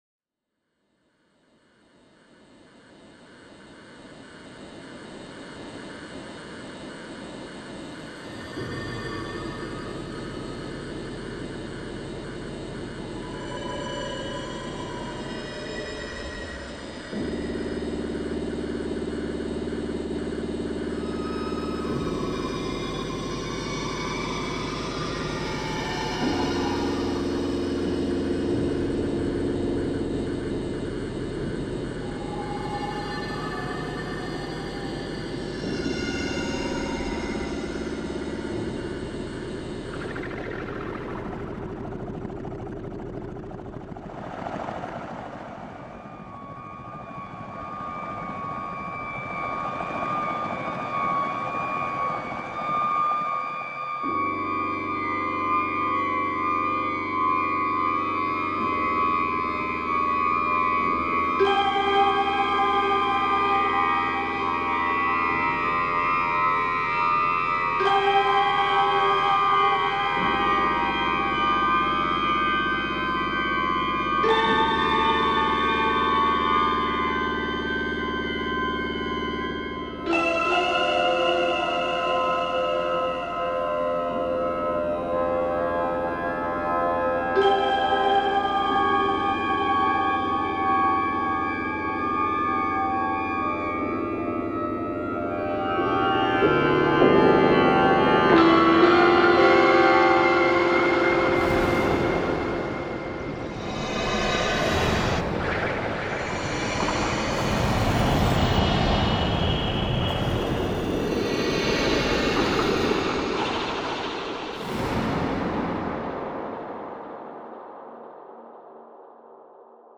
The metal creaks and bends as you pass.